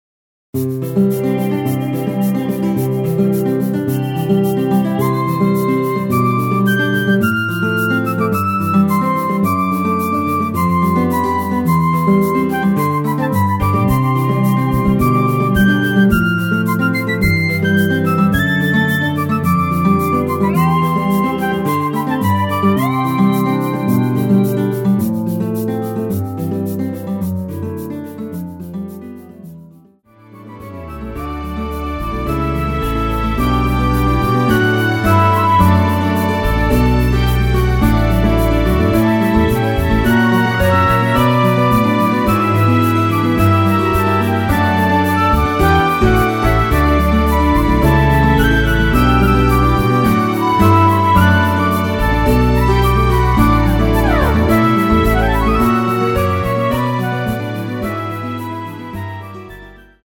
원키 멜로디 포함된 MR입니다.
앞부분30초, 뒷부분30초씩 편집해서 올려 드리고 있습니다.